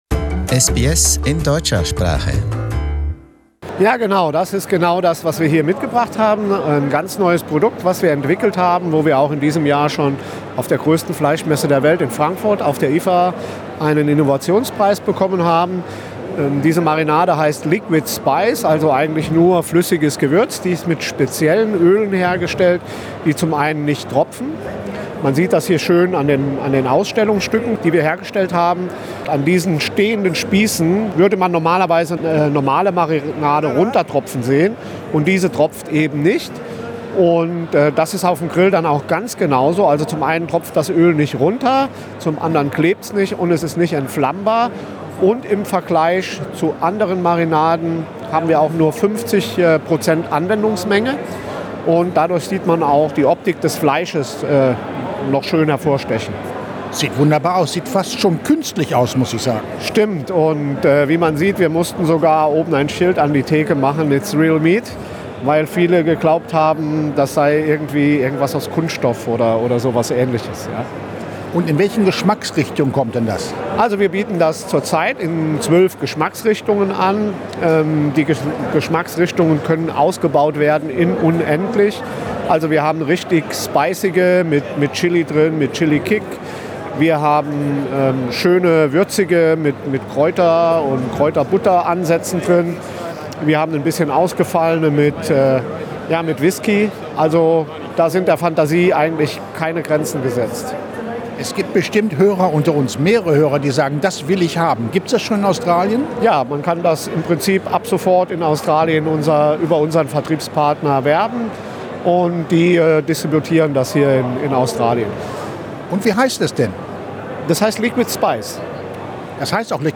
The best selection was on show at the recent Fine Food Exhibition in Sydney.